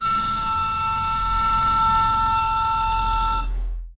ding-approval-tone-convey-c5adyz6a.wav